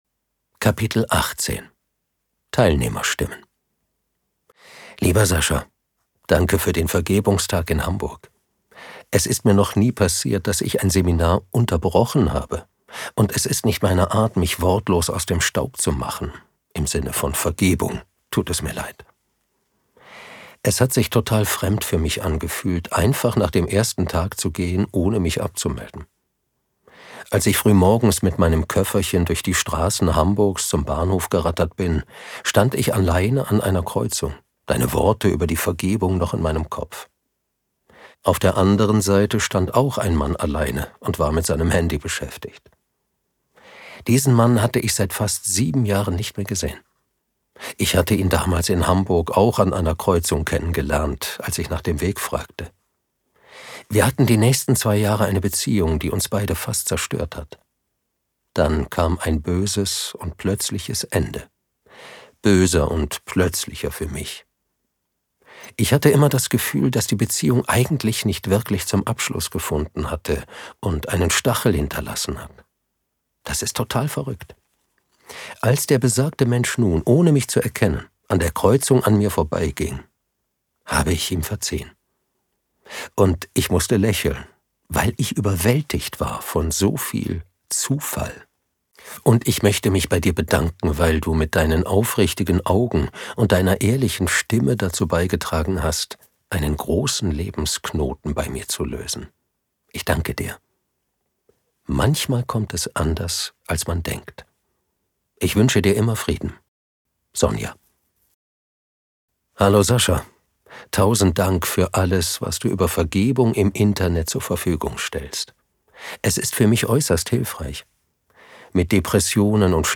Das Buch als Hörbuch
Hörbuch über Vergebung und inneren Frieden
In dieser Hörprobe begleite ich dich durch den Weg zu mehr innerem Frieden – ruhig, Schritt für Schritt.